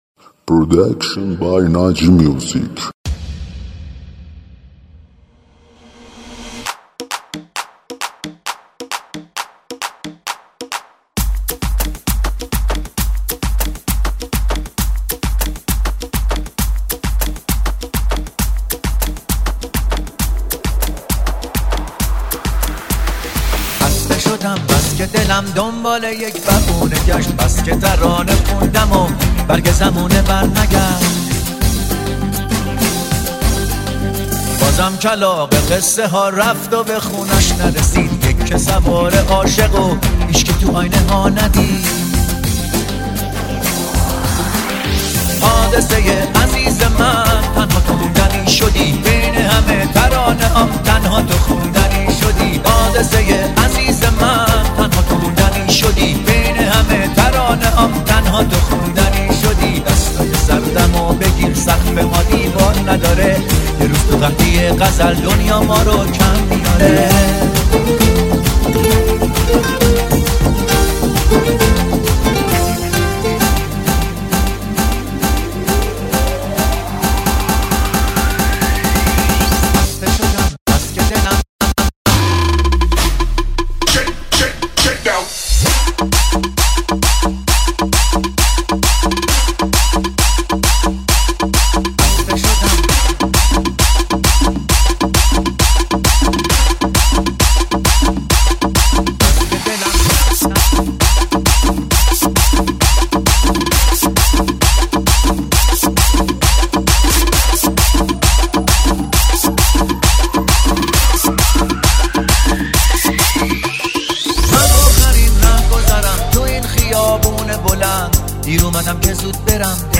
آهنگ شاد تریبال مخصوص پارتی و رقص
ریمیکس های شاد تریبالی